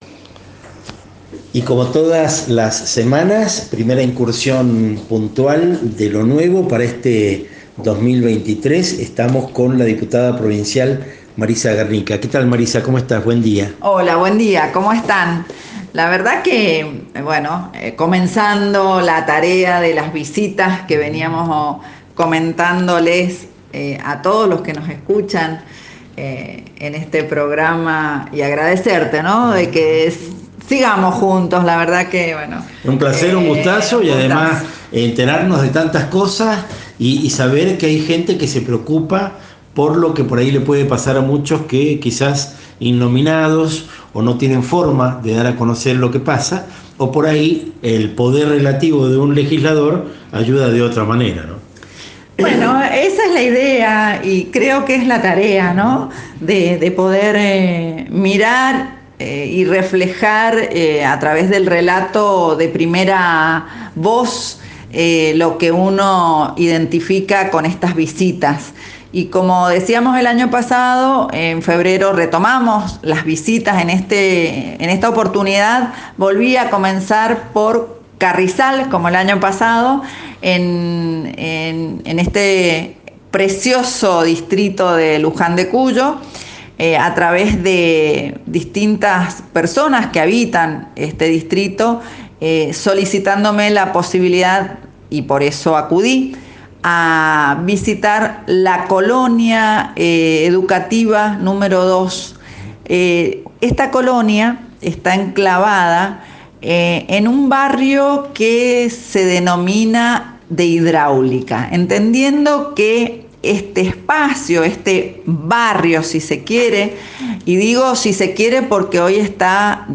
Entrevista: Marisa Garnica, Diputada Provincial, 16 de febrero de 2023